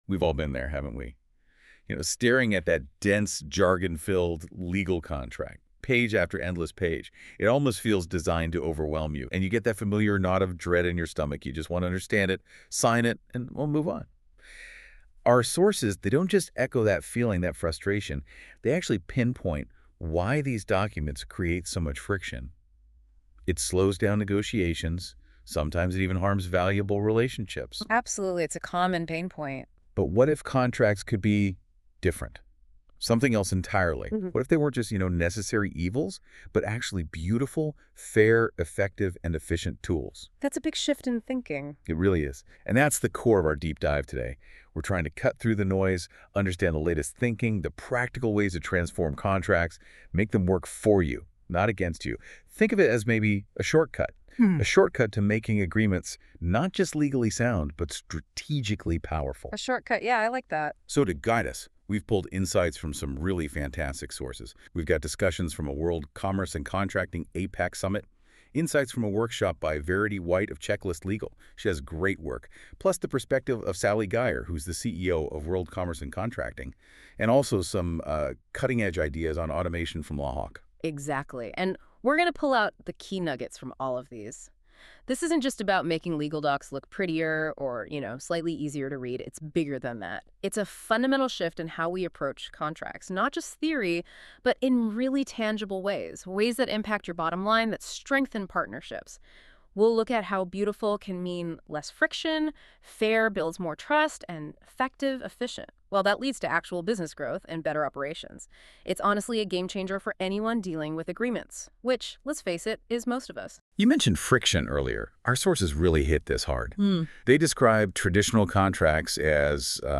Google Notebook LM - Contract Simplification and Automation.m4a